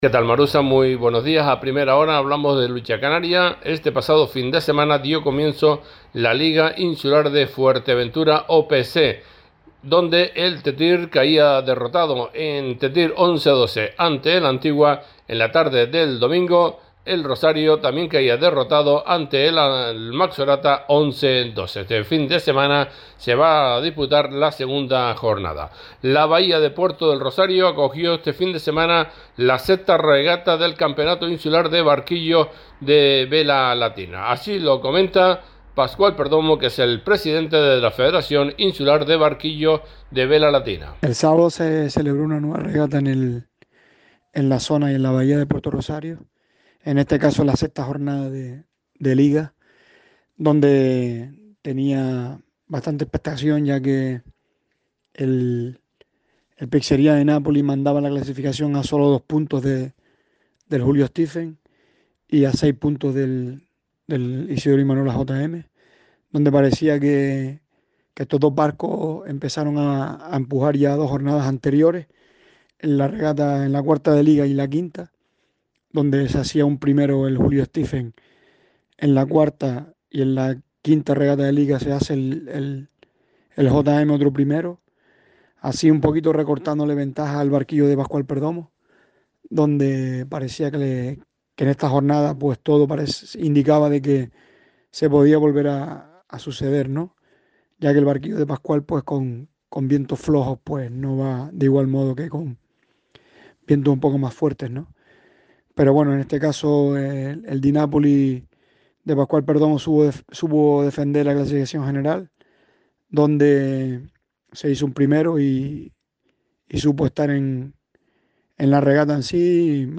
Entrevistas A Primera Hora